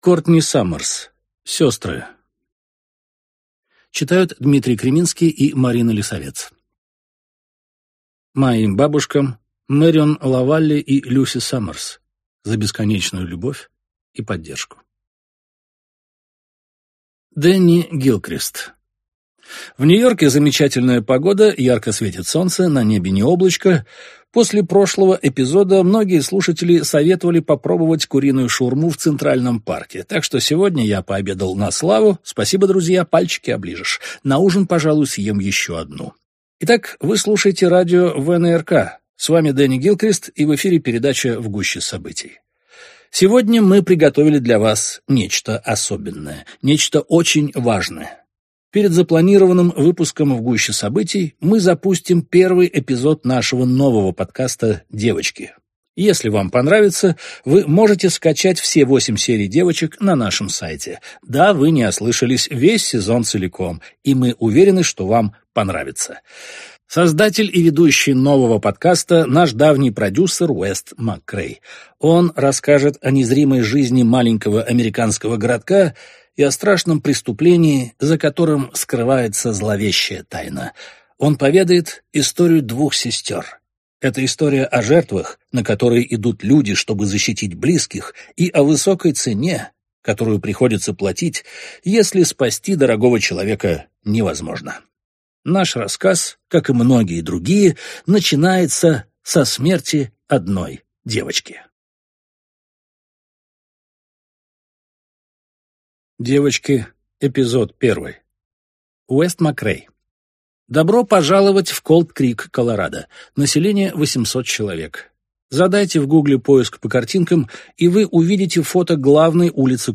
Аудиокнига Сестры | Библиотека аудиокниг
Прослушать и бесплатно скачать фрагмент аудиокниги